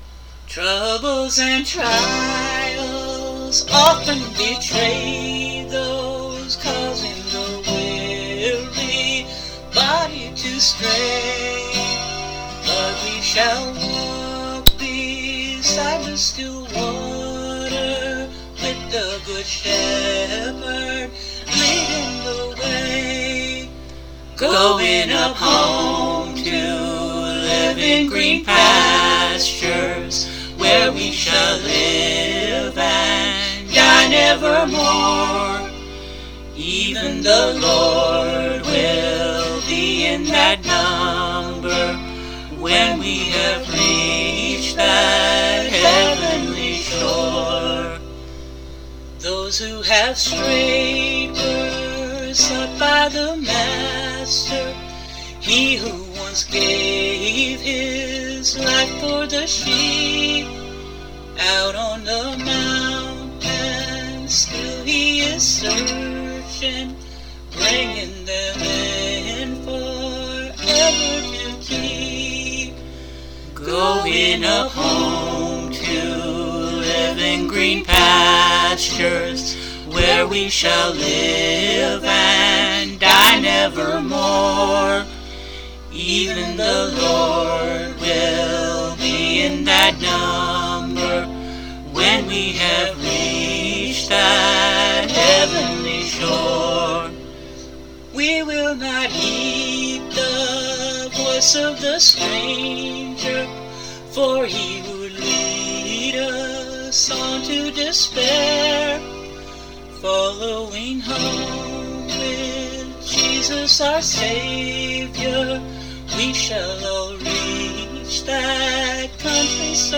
12 String Guitar, All Vocals